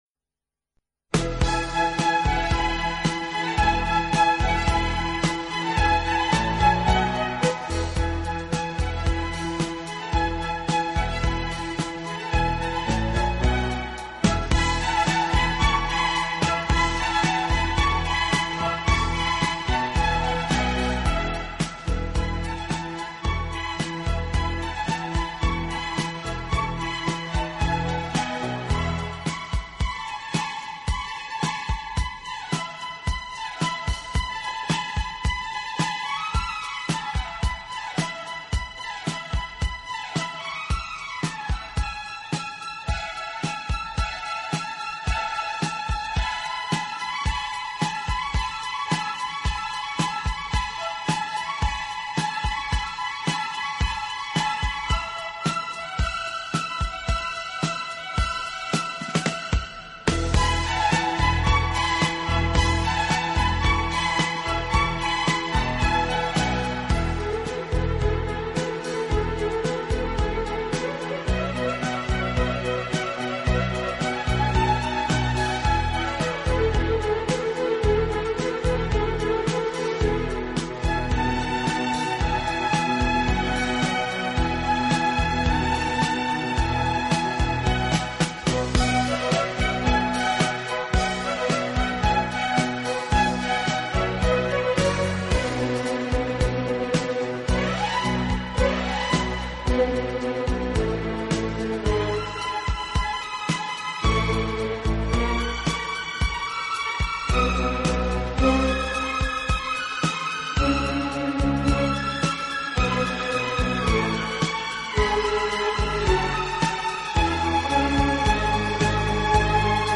轻音乐